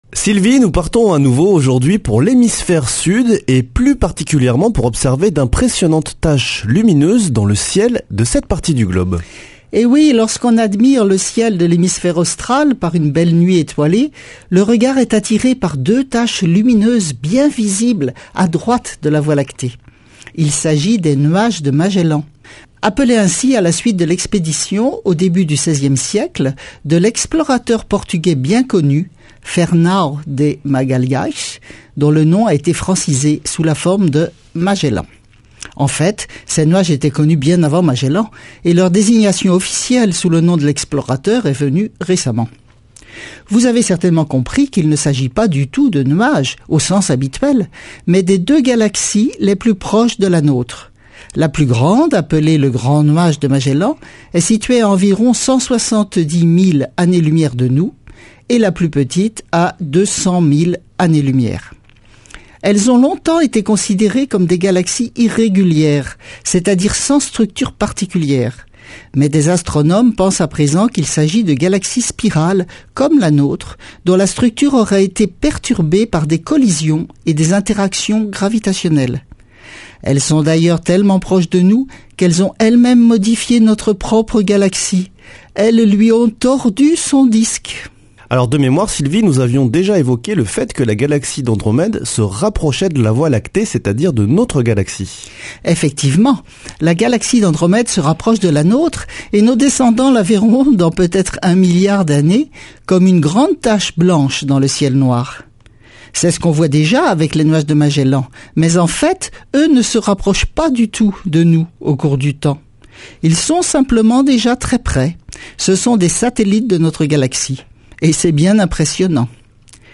Chronique Astrophysique
Speech